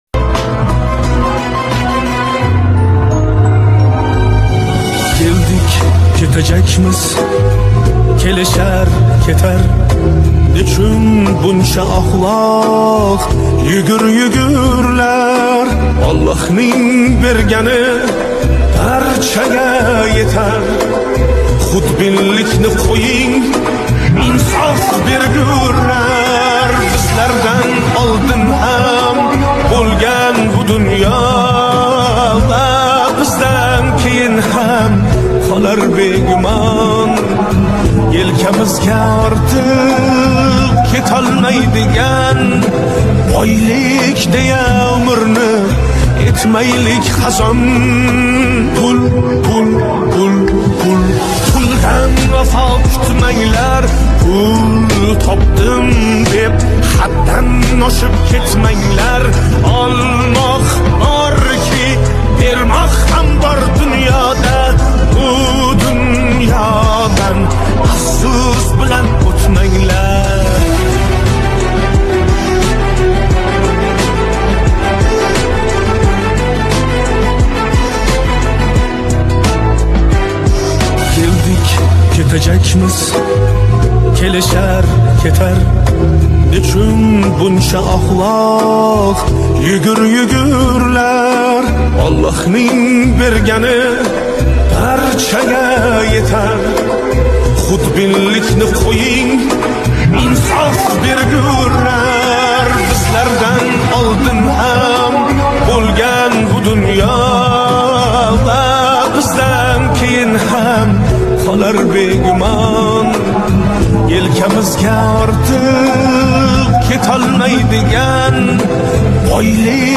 Жанр: Узбекские